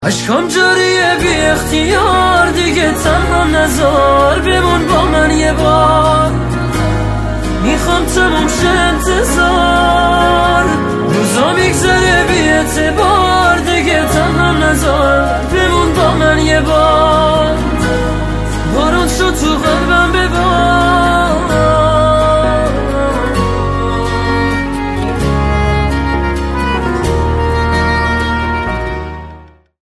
رینگتون نرم و احساسی باکلام